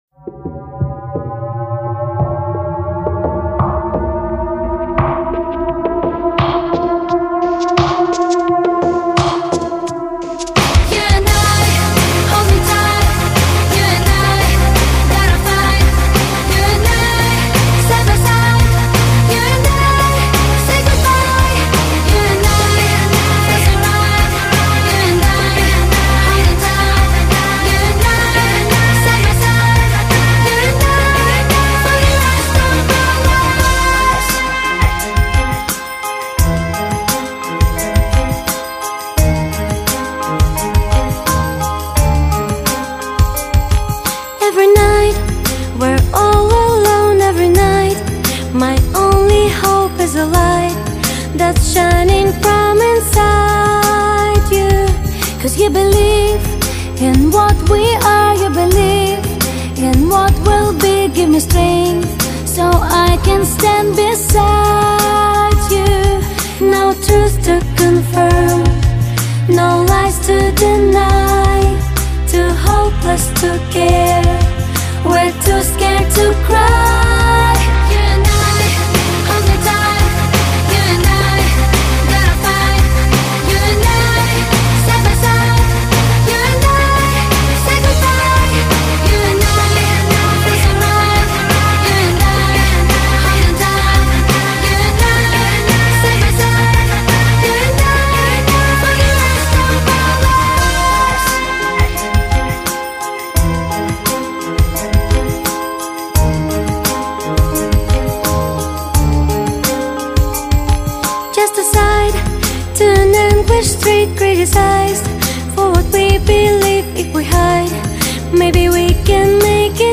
Стиль(音乐类型): Pop